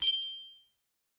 starPickup.ogg